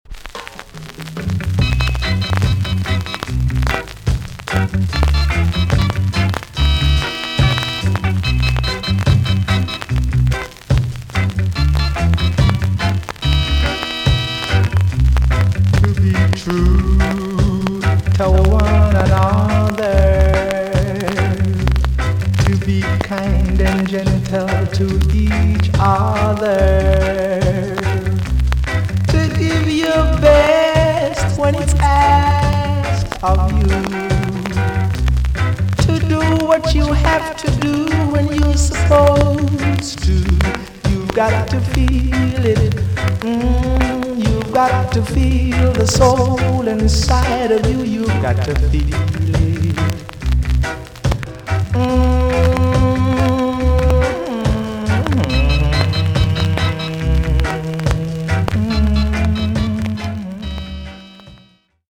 TOP >SKA & ROCKSTEADY
VG ok チリノイズが入ります。